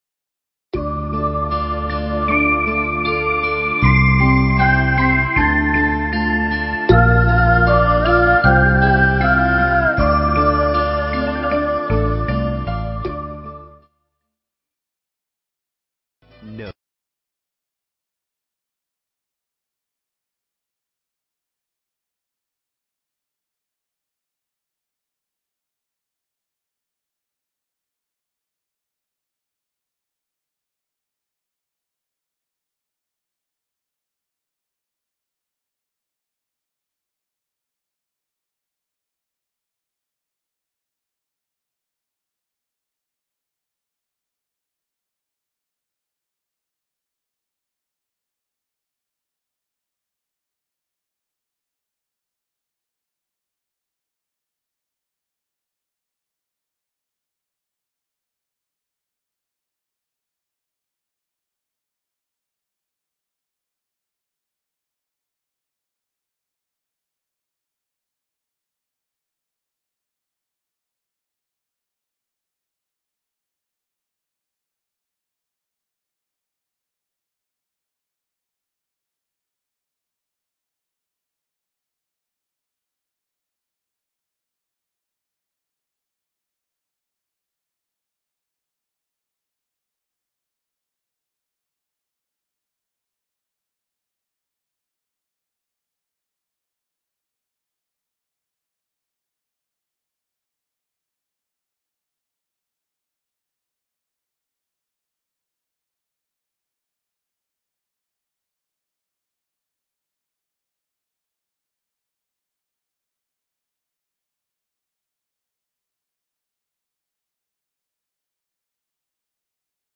Pháp thoại